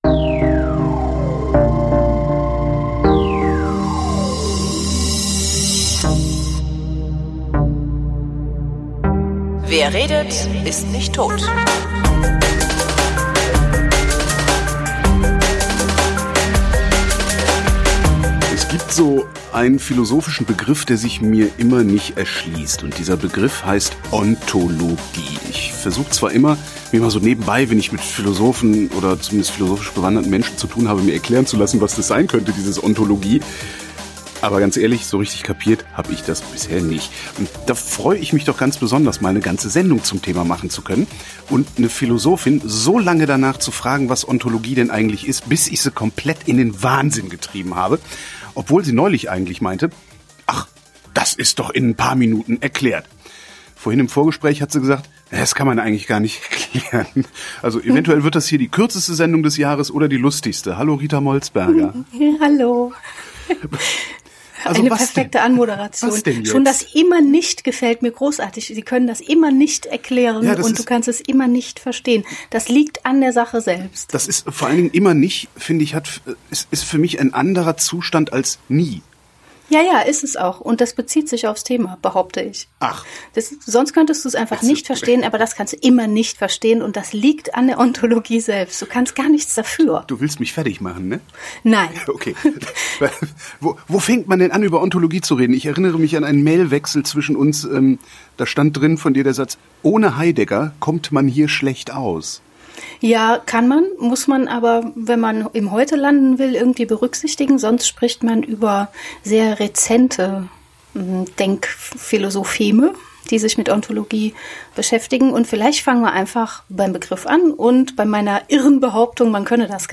wrint: gespräche zum runterladen